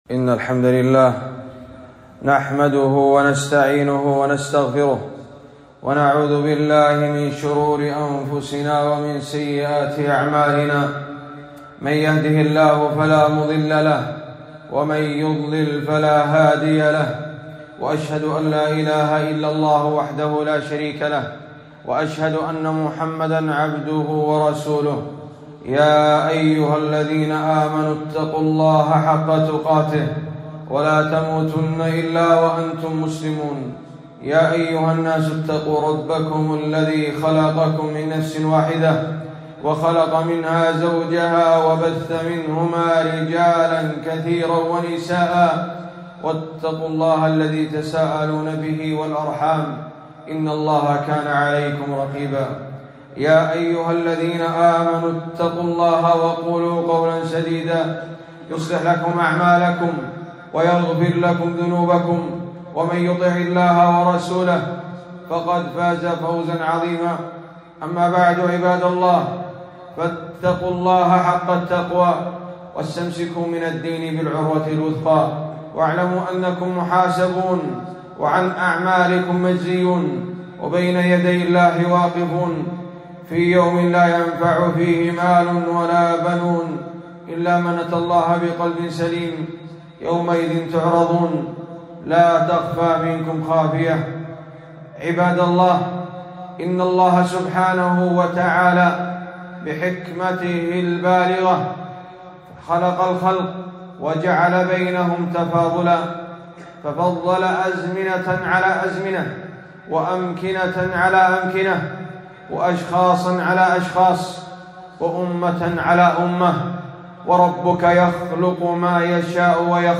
خطبة - شهر شعبان راتبة رمضان